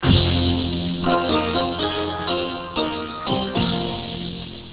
Chinese Chime